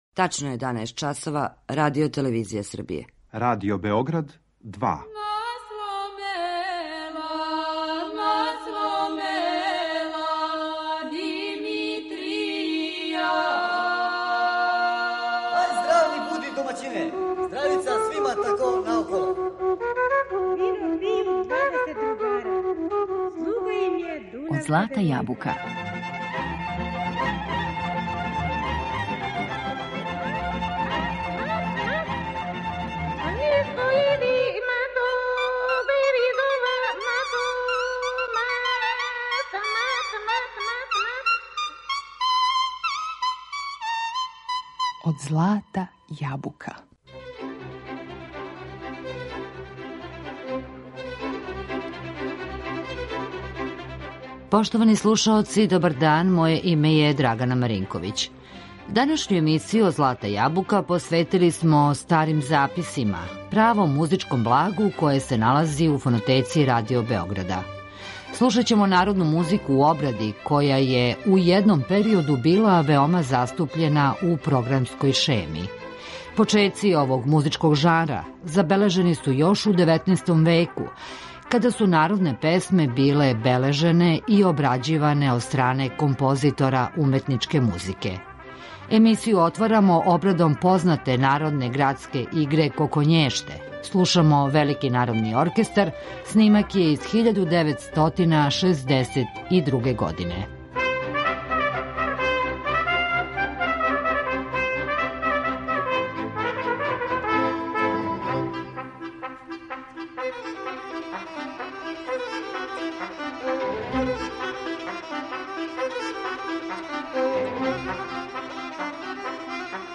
Народне песме и игре у уметничкој обради
У данашњој емисији Од злата јабука настављамо нашу музичку шетњу кроз време уз помоћ старих записа који су сачувани у Фонотеци Радио Београда.
Пред нама су данас народне песме и игре у уметничкој обради.